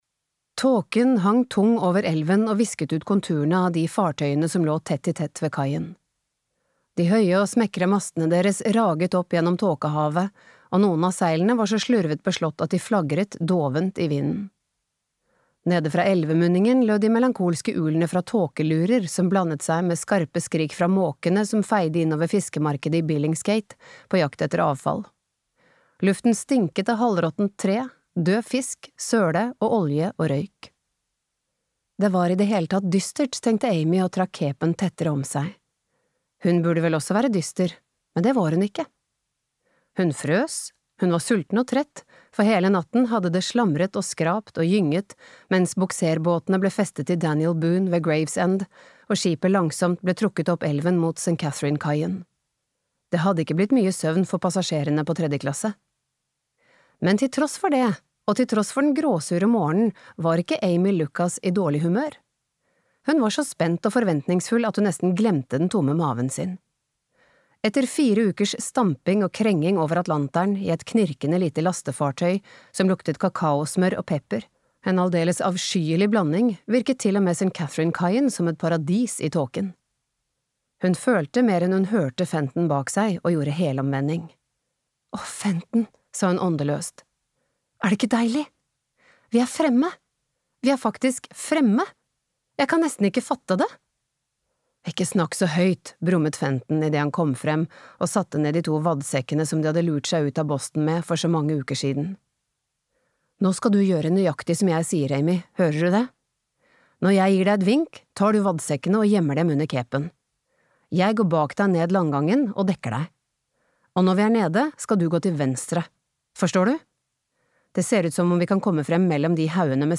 Skygger fra fortiden (lydbok) av Claire Rayner